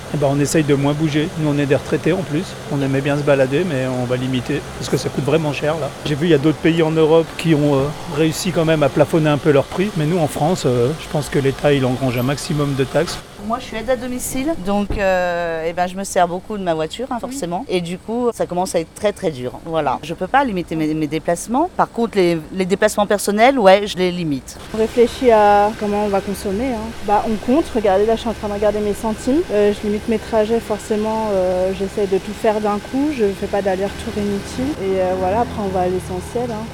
Nous vous avons posé la question, devant la pompe, ces derniers jours en Haute-Savoie.